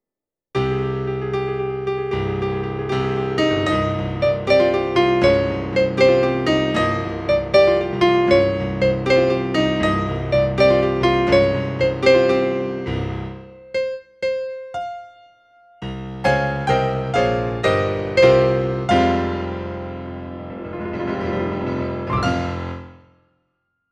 ECC Soprano
ECC-Soprano.mp3